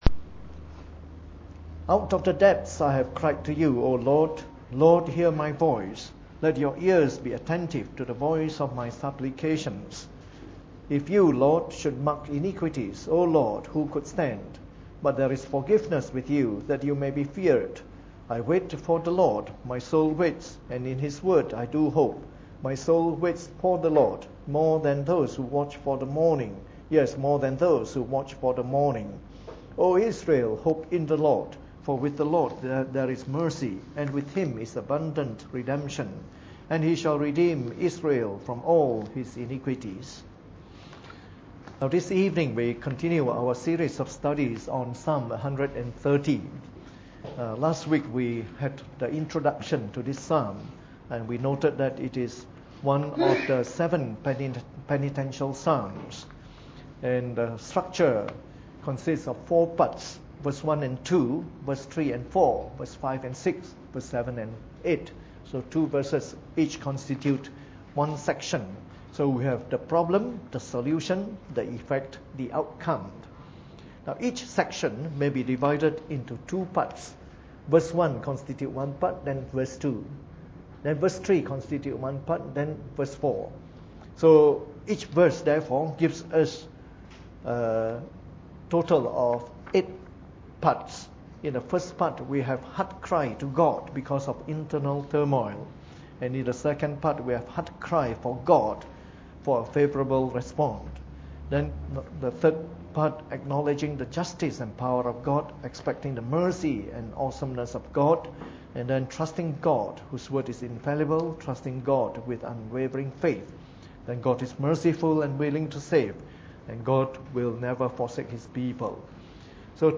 Preached on the 10th of July 2013 during the Bible Study, from our series of talks on Psalm 130.